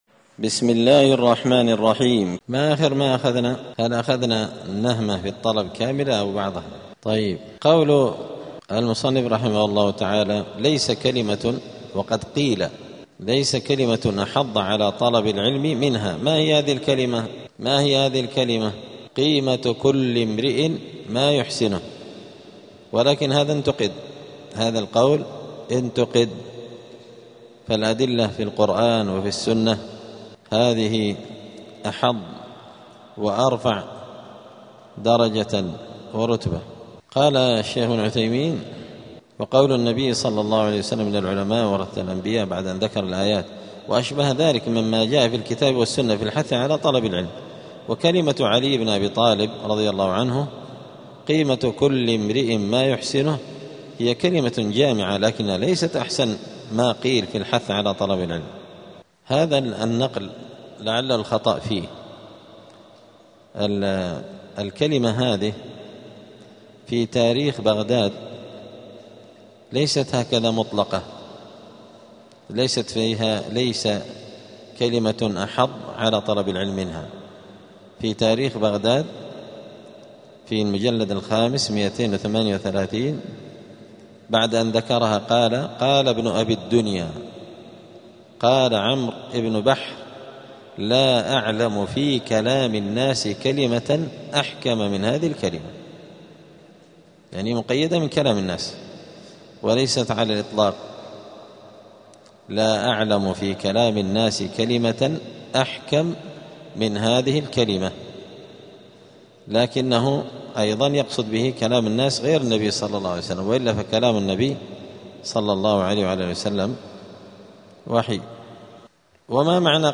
دار الحديث السلفية بمسجد الفرقان قشن المهرة اليمن
*الدرس الرابع والخمسون (54) فصل آداب الطالب في حياته العلمية {النعمة في الطلب}.*